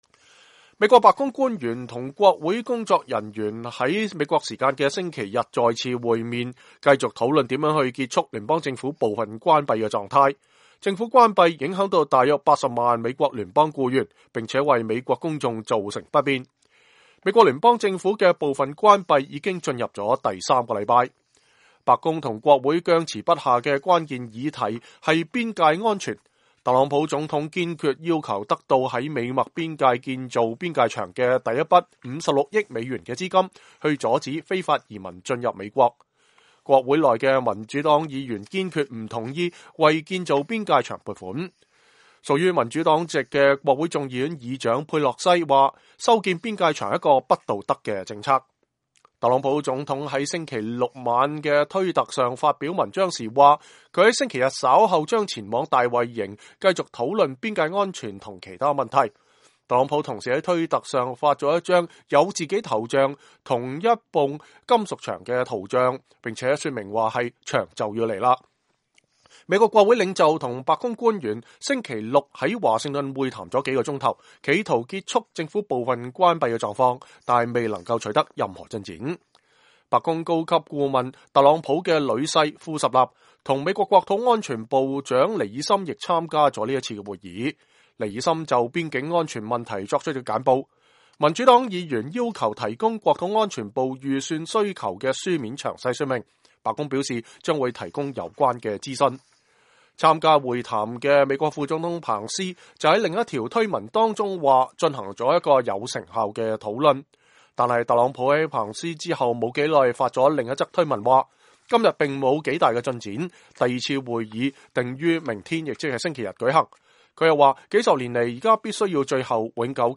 2019-01-06 美國之音視頻新聞: 白宮與國會星期日再次商討結束政府部分關閉